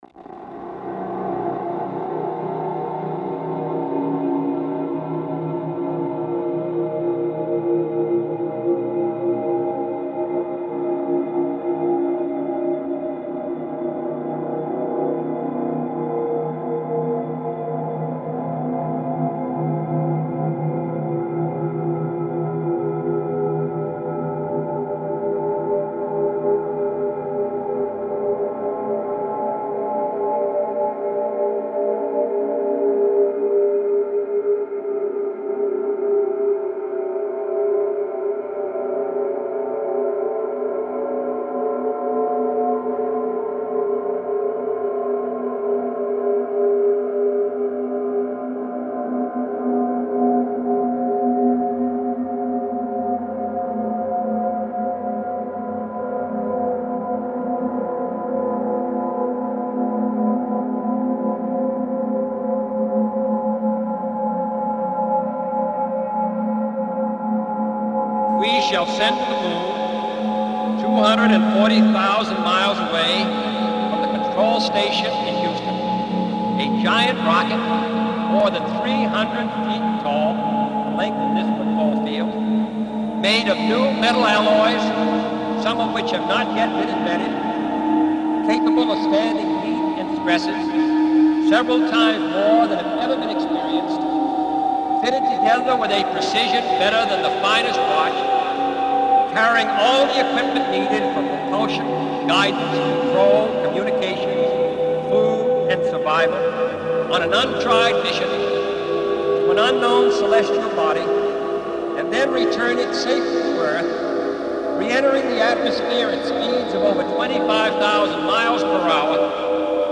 generated harmonic series